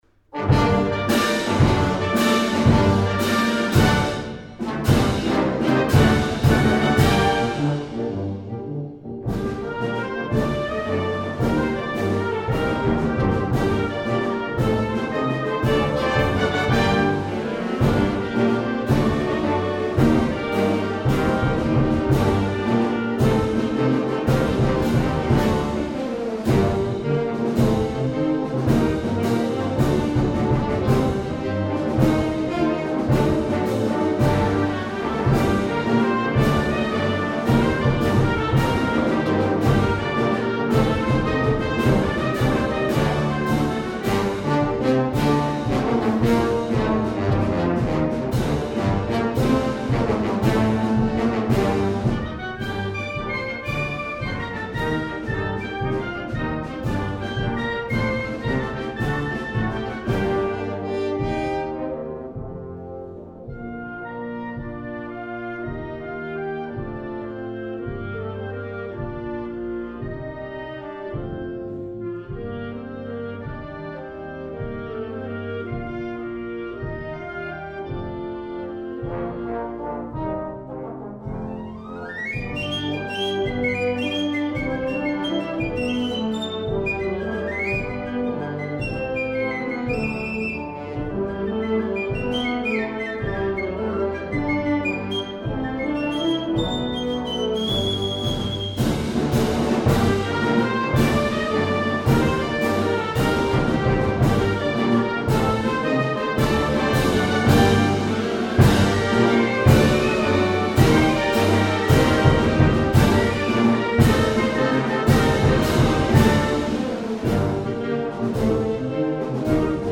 You can hear here the specially commissioned march played by the
RAF band at the launch dedicated to Sir George White.
raf_band_at_bac_100_launch.mp3